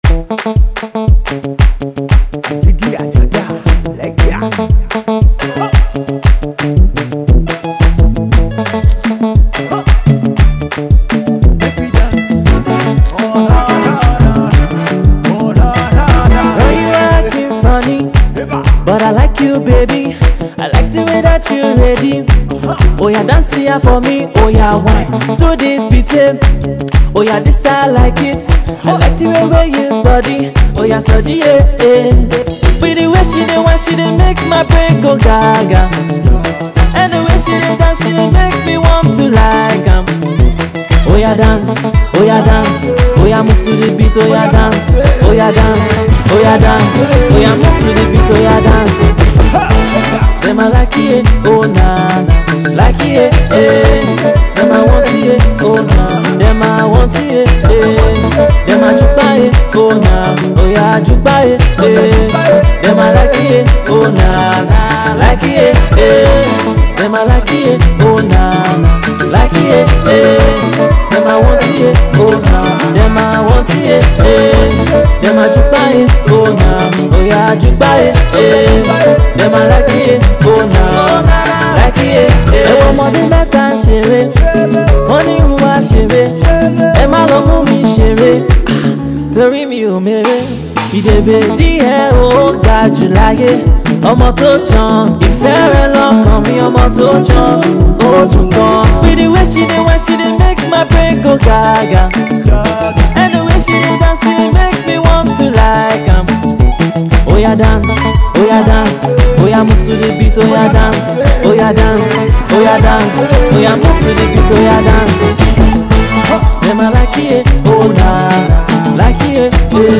Pop
R And B